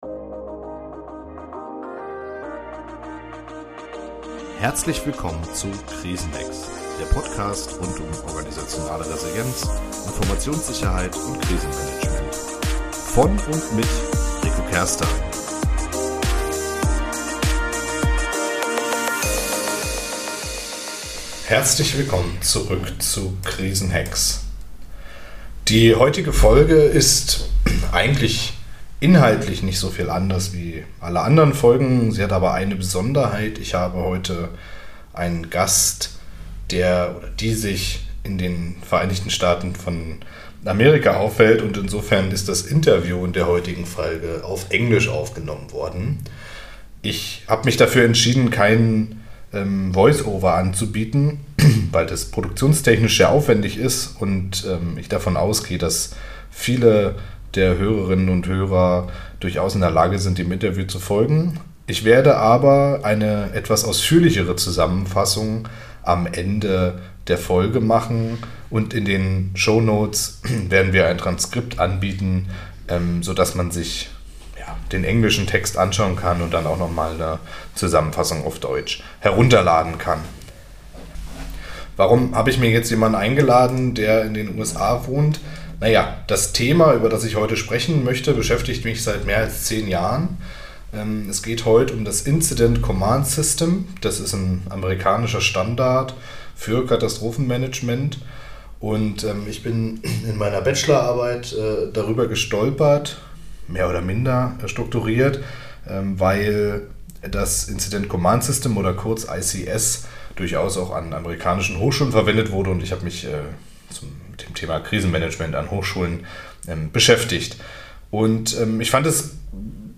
Die beiden Experten sprechen über das Incident Command System (ICS) und das National Incident Management System (NIMS). Dabei handelt es sich um zwei zentrale Strukturen für das Katastrophenmanagement in den USA.